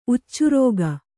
♪ uccurōga